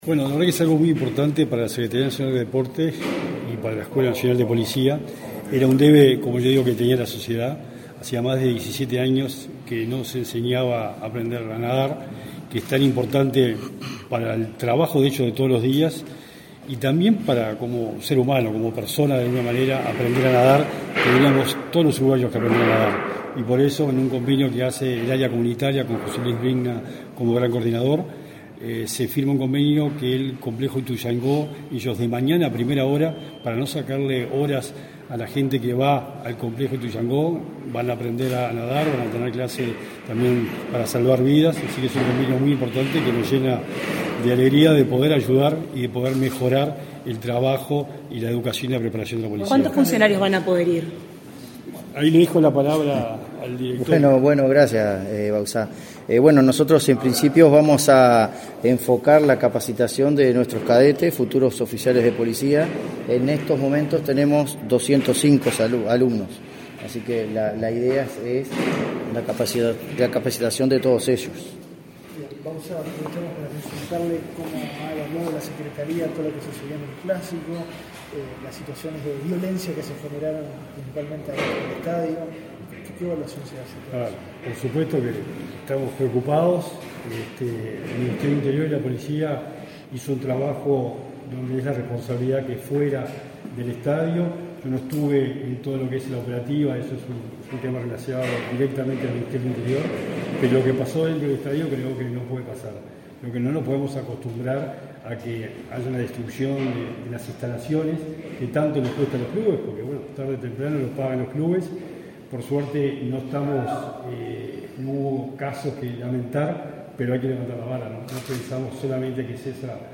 Declaraciones a la prensa del secretario del Deporte, Sebastián Bauzá, y del titular de la Dirección de la Educación Policial, Efraín Abreu
Tras participar en la firma del convenio entre el Ministerio del Interior y la Secretaría Nacional del Deporte, este 7 de setiembre, que posibilitará que cadetes de la Policía utilicen la piscina del complejo Ituzaingó, Bauzá y Abreu efectuaron declaraciones a la prensa.